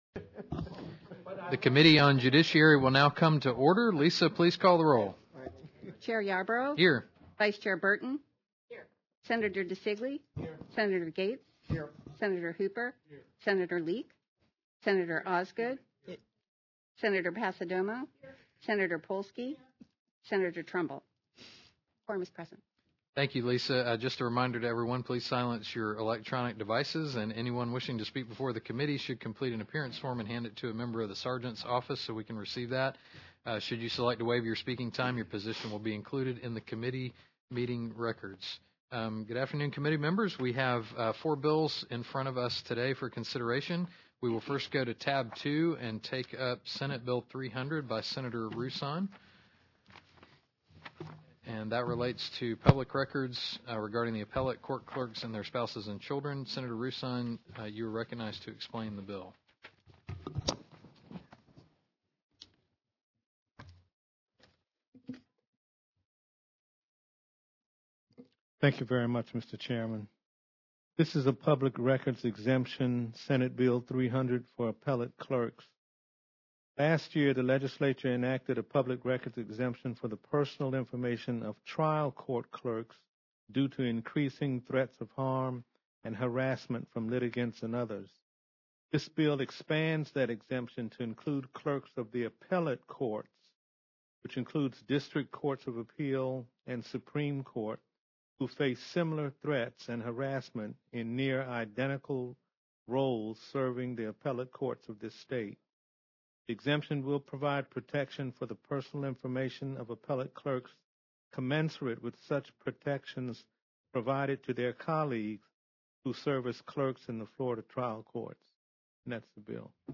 Military and Veterans Affairs, Space, and Domestic Security Location: 301 Senate Building Meeting Records Meeting Notice [PDF] Meeting Packet [PDF] Attendance [PDF] Expanded Agenda [PDF] Audio [MP3] Video [MP4]